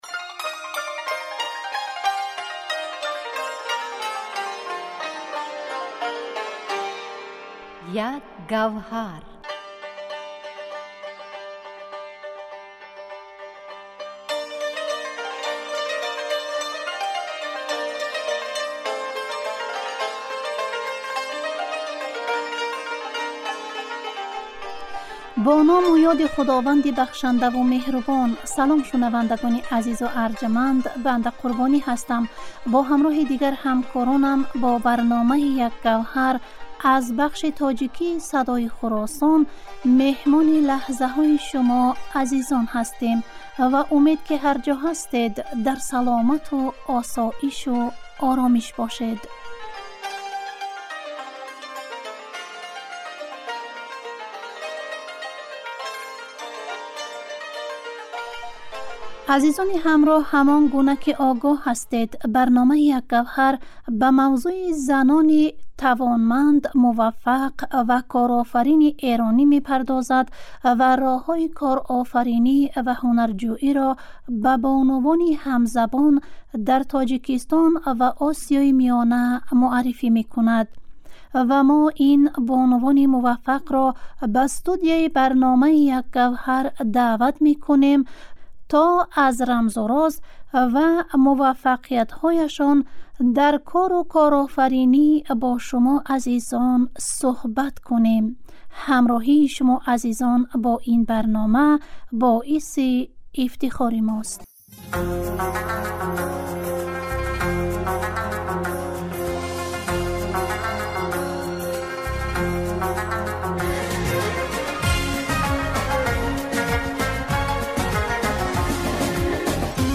Дар силсилабарномаҳои "Як гавҳар", ҳар ҳафта ба масоили марбут ба занони эронӣ пардохта мешавад ва роҳҳои корофаринӣ ва ҳунарҷӯиро ба занони тоҷик ва Осиёи Миёна муъаррифӣ мекунад. Ин барнома, панҷшанбеи ҳар ҳафта аз Садои Хуросон пахш мешавад.